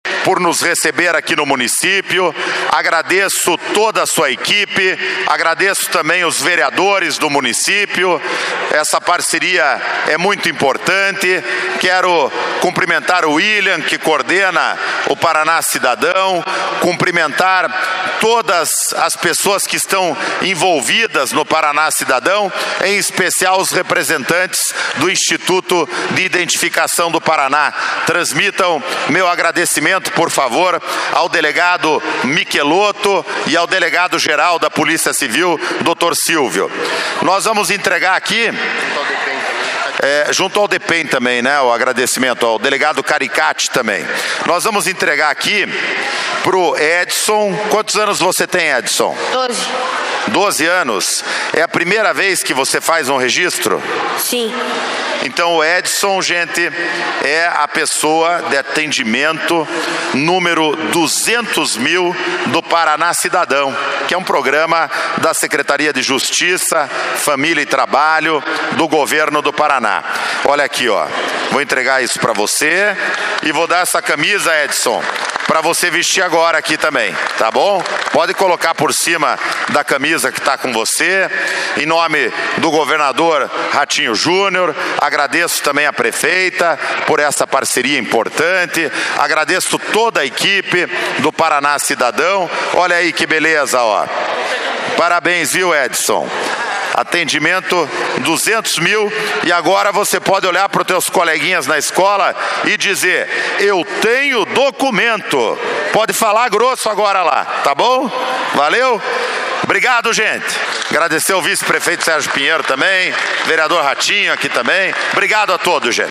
200 mil atendimentos Parana Cidadão em Colombo discurso Secretário Ney Leprevost